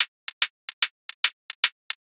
UHH_ElectroHatC_110-01.wav